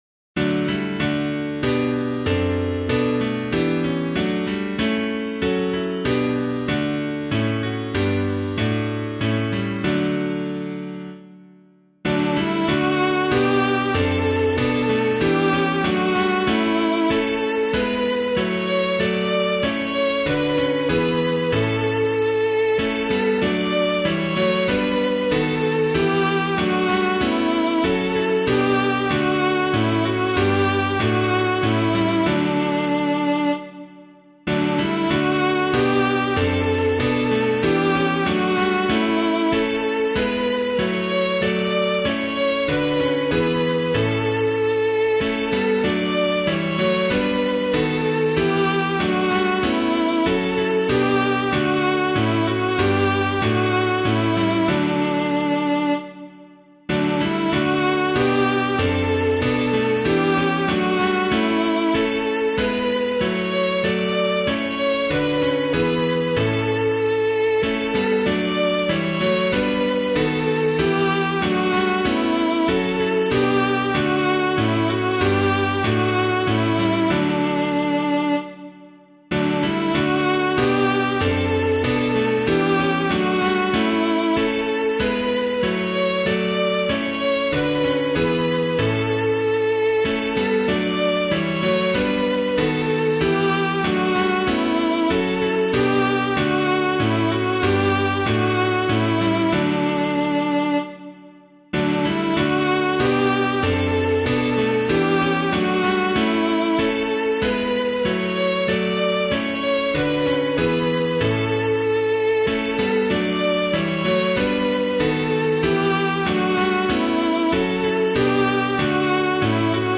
piano
This Is My Will My One Command [Quinn - EISENACH] - piano.mp3